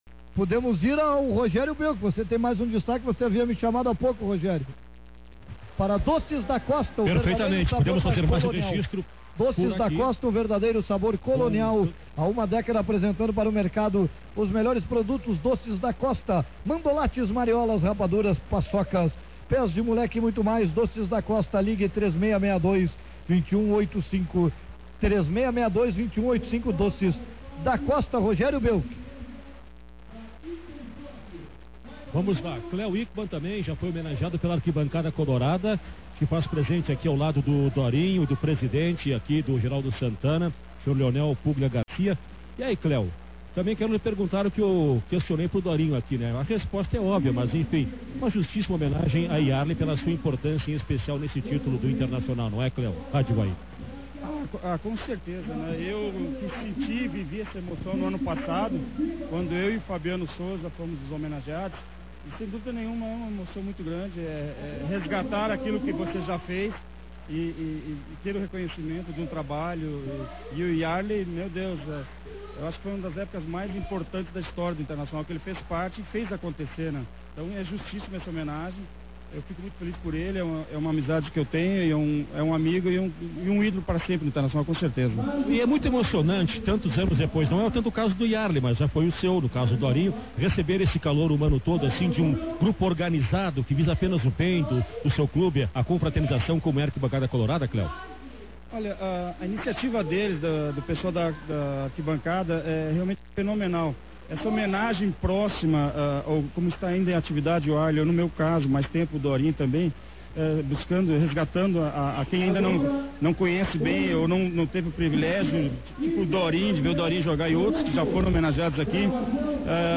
Transmissão Rádio Guaíba Homenagem Iarley – parte 07